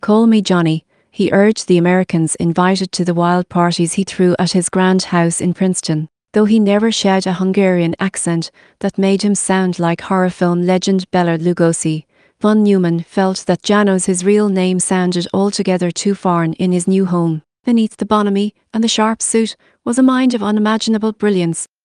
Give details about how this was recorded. opus at 45kbps should be good enough for mono/voice. p.s. this one is 40.5 kbps